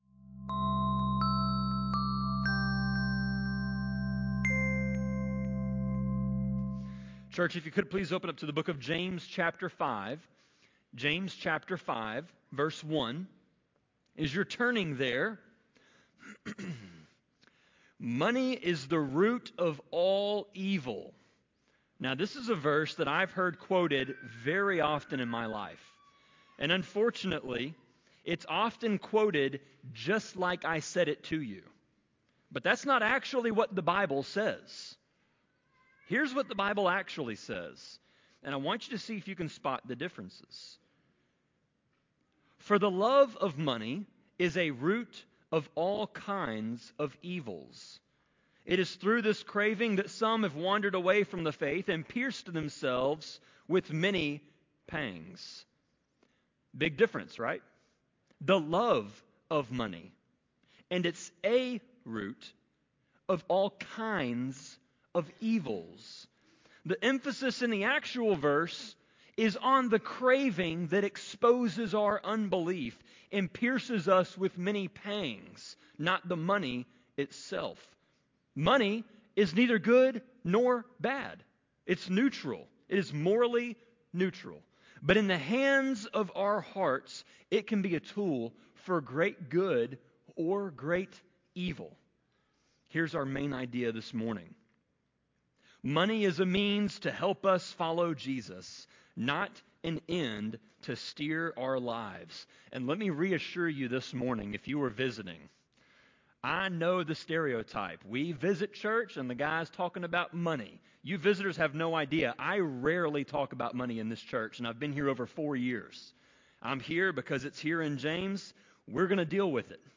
Sermon-25.8.3-CD.mp3